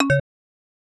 Button_scale_2.wav